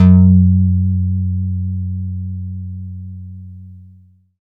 SYN HARPLI00.wav